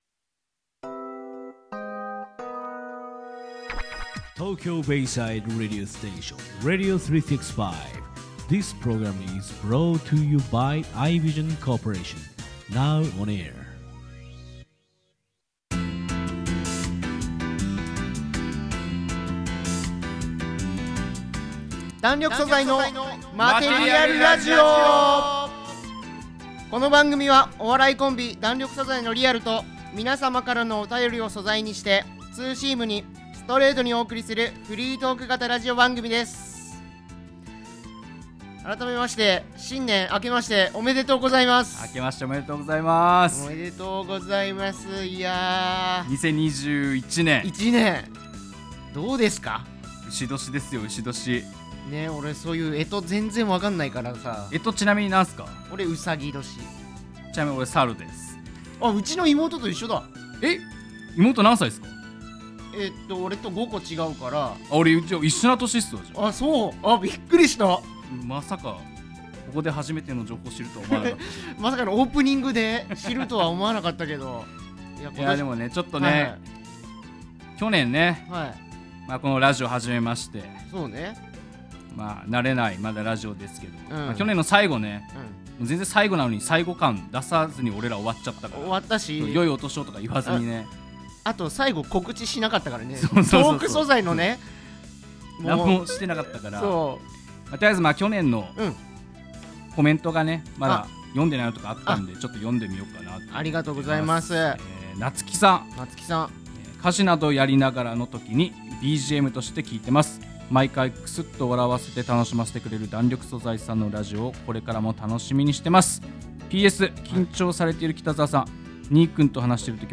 お笑いコンビ弾力素材の5回目のラジオです！！